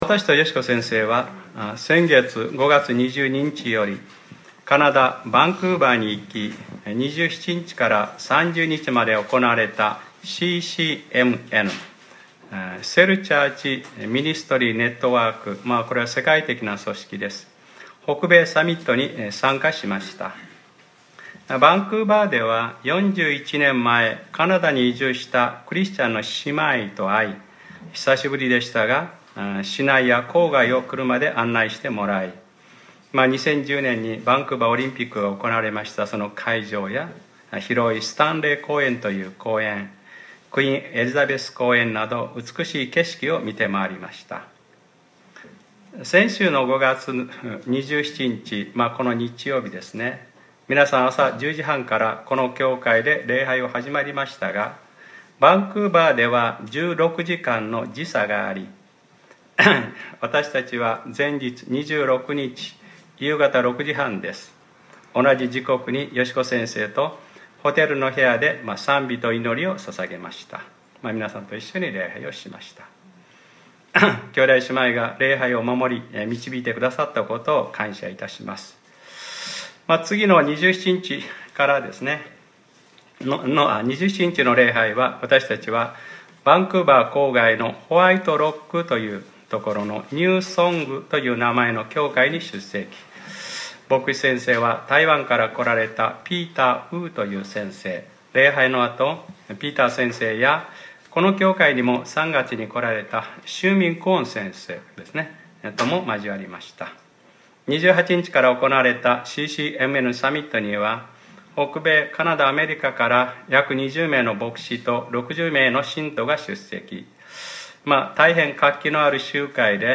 2018年06月03日（日）礼拝説教『新しい契約』